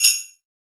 WJINGLE BE2Q.wav